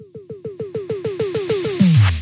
OLIVER_fill_drum_loop_100_03_D